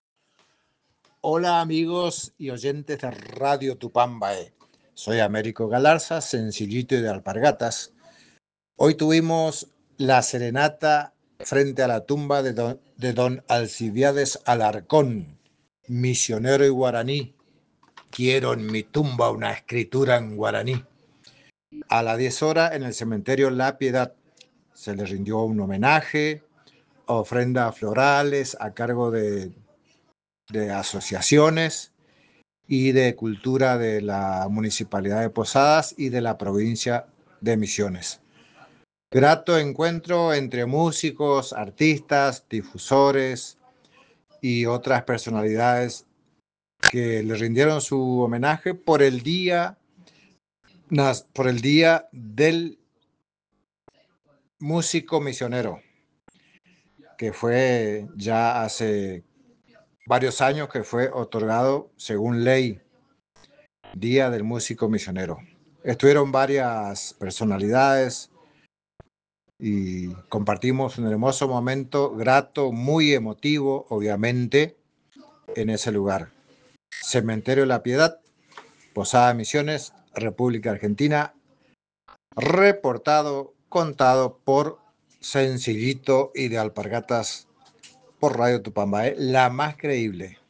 En diálogo con la Redacción de Radio Tupambaé